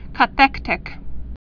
(kə-thĕktĭk)